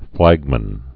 (flăgmən)